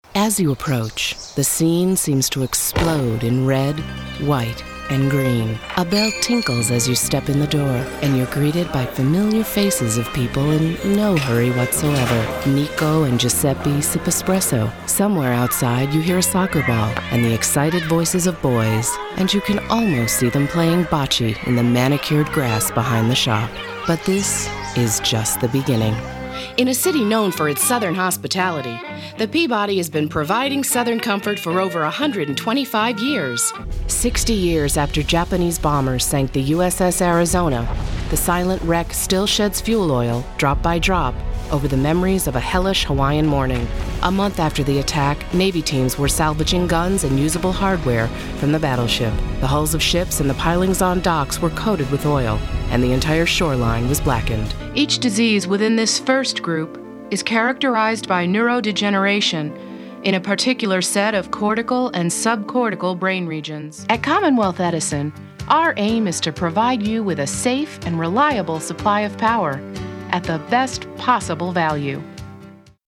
English-speaking, mid vocal range, commercials, corporate, imaging, animation, home studio, professional, warm, intelligent, authentic, friendly
Sprechprobe: Industrie (Muttersprache):
VOICE TRAITS: wry, warm, sexy, authoritative, friendly, fun, real, mom, genuine, conversational, matter-of-fact, high energy, professional